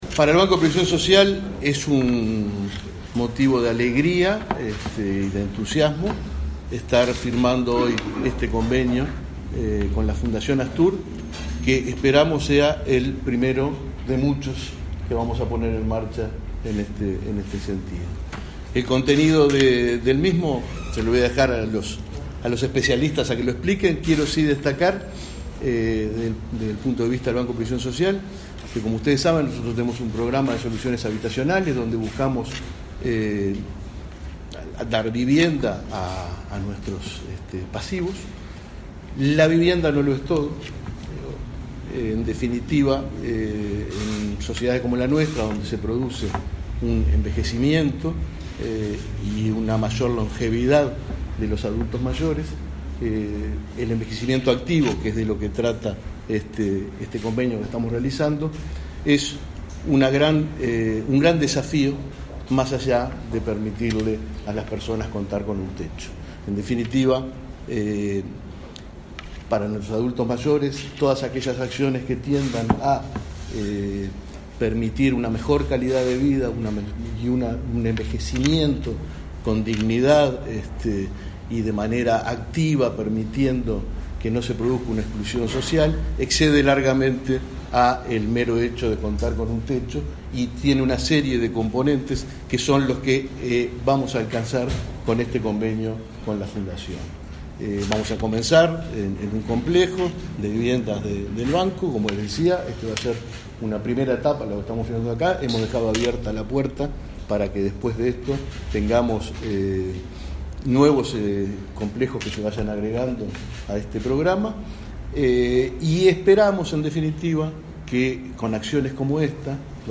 Palabras del presidente del BPS